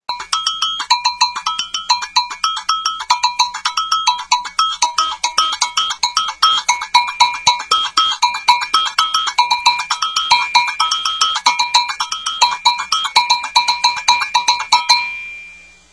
אגוגו
.האגוגו הוא הכלי בעל הצליל הגבוה ביותר בבטוקדה
.האגוגו הינו צמד או שלישיית פעמוני מתכת מחוברים ביניהם
.מכל הכלים, האגוגו הוא הקרוב ביותר לצליל האפריקאי
agogo.wma